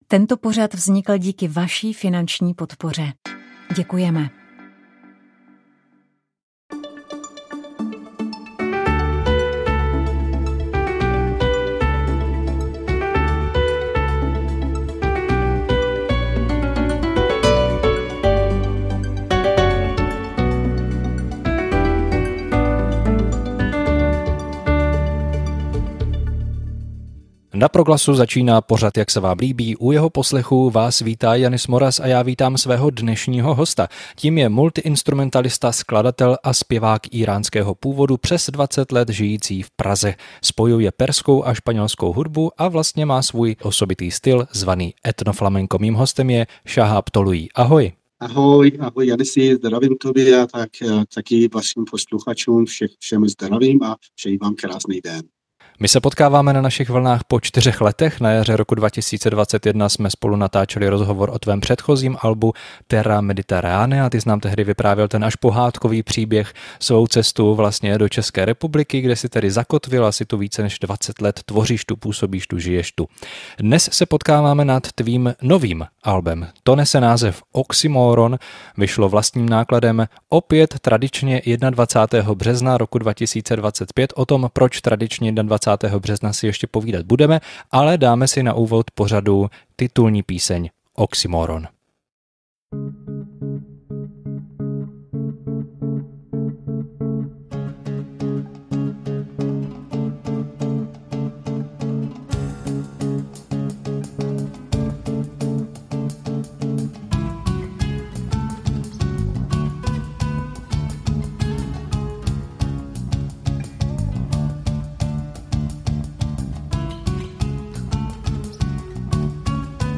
Anglický bluesový pianista
vystoupí v našem studiu živě v duu s bubeníkem